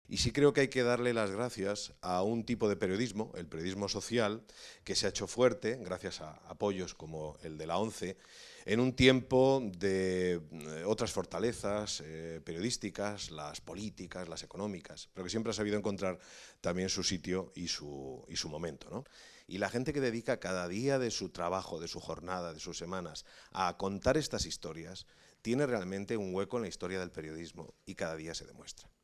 manifestó formato MP3 audio(0,55 MB) el secretario de Estado de Comunicación, Miguel Ángel Oliver, como colofón del acto de entrega de la XXI  edición de los Premios Tiflos de Periodismo Social que concede la ONCE y que tuvo lugar, en el marco de la Facultad de Ciencias de la Información de la Complutense de Madrid, el pasado 9 de mayo.